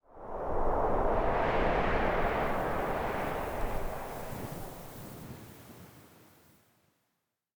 housewind10.ogg